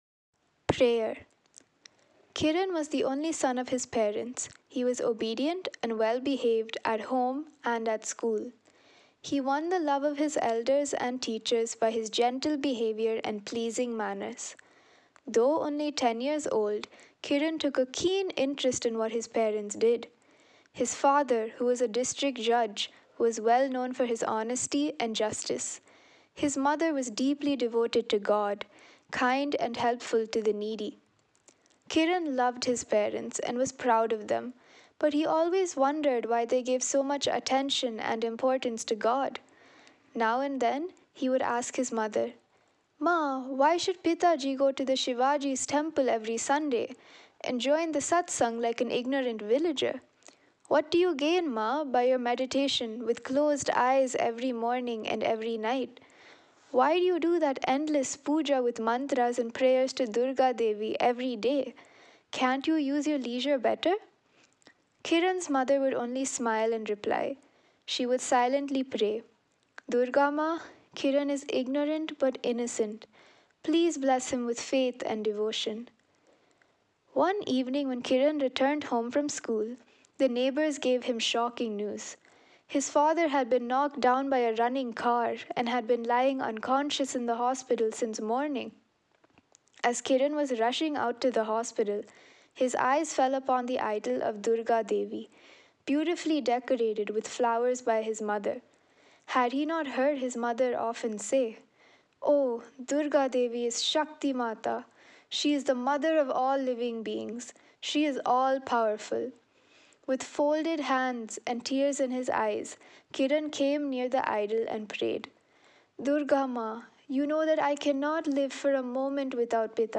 prayer_story_audio.mp3